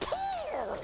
peeyew.wav